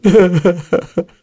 Esker-Vox_Sad.wav